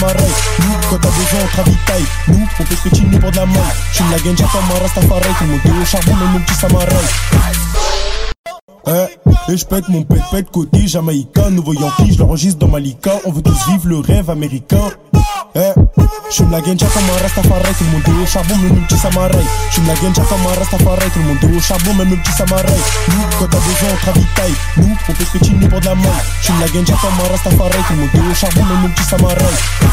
Жанр: Поп
# French Pop